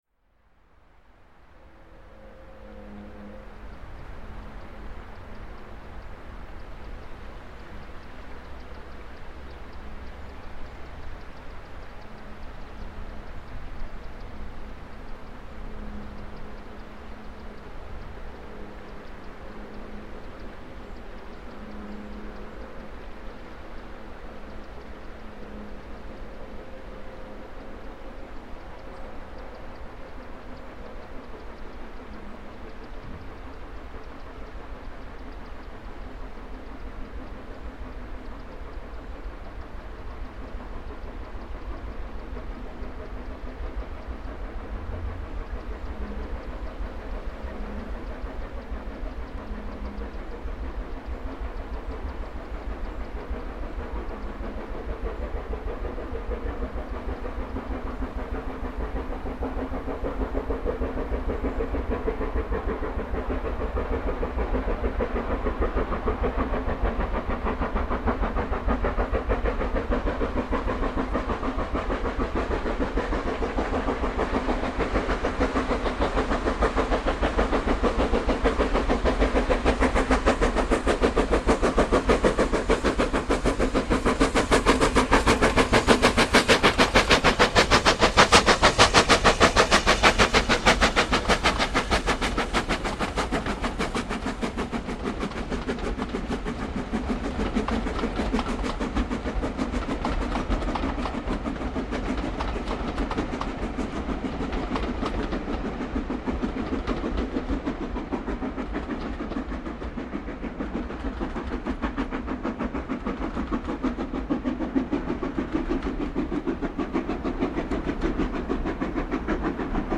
75029 bei Water Ark, vom östlichen Hang aus, mitten im hohen Frankraut stehend, um 17:00h am 08.08.2000.   Hier anhören: